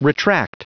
Prononciation du mot retract en anglais (fichier audio)
Prononciation du mot : retract